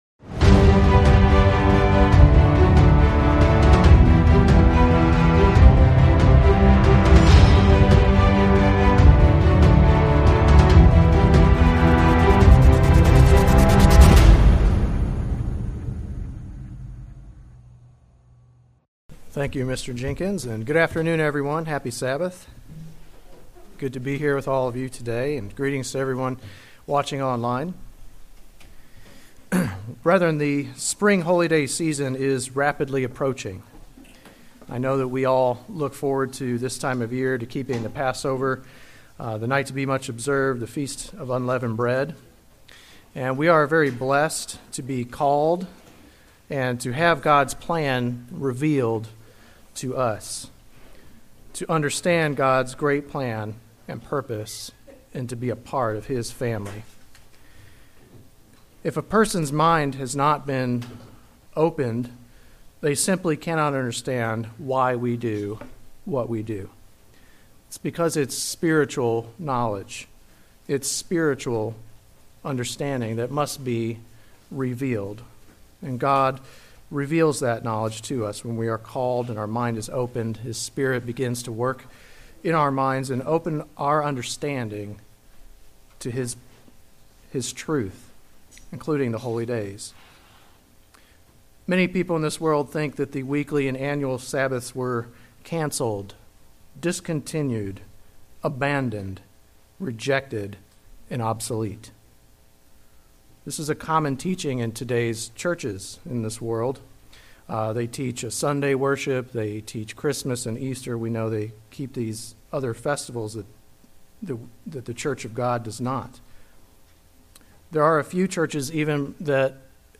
In this sermon we will discuss a review of Colossians Chapter 2.